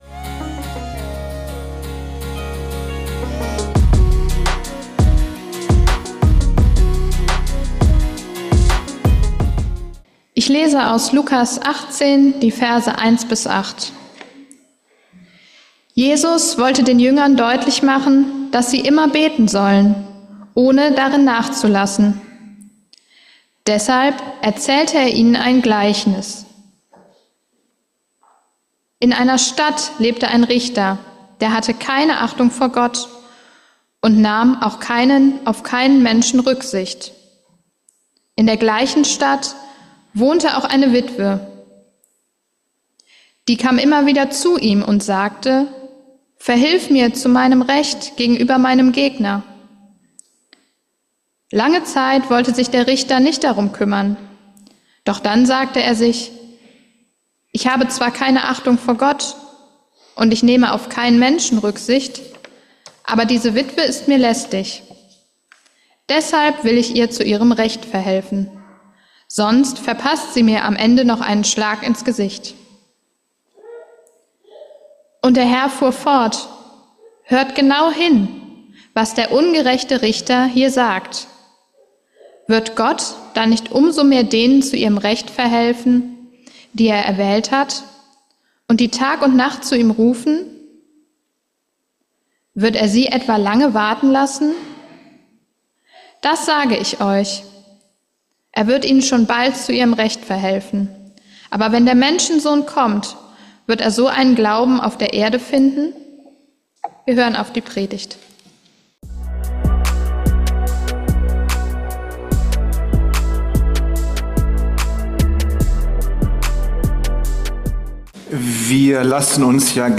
Die bittende Witwe - hat Beten überhaupt Sinn? ~ Geistliche Inputs, Andachten, Predigten Podcast